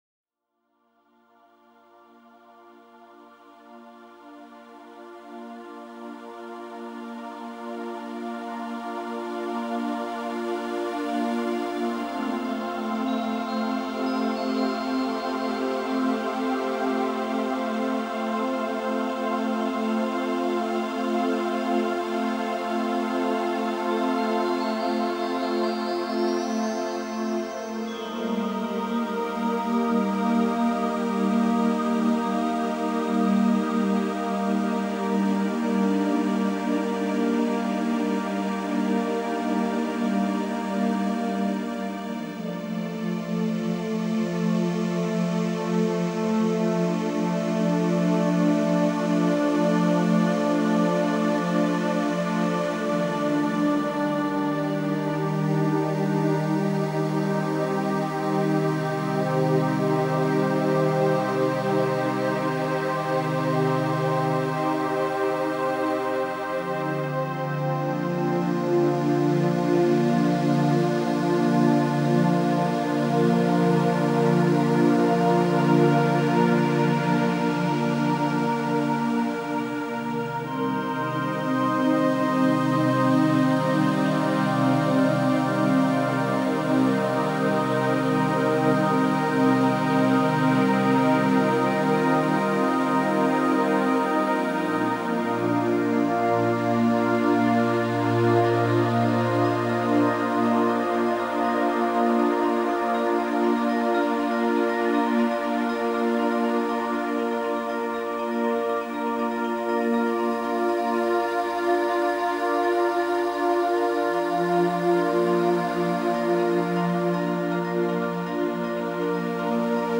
music for meditation, relaxation, and stress reduction